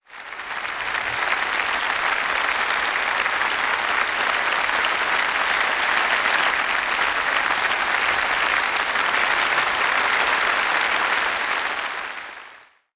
BGM影视剧中众人鼓掌热烈鼓掌声下载
BGM免费下载介绍：背景音乐为影视剧中众人鼓掌热烈鼓掌声，格式为 wav， 大小3 MB， 该BGM音质清晰、流畅，源文件无声音水印干扰， 欢迎收藏国外素材网。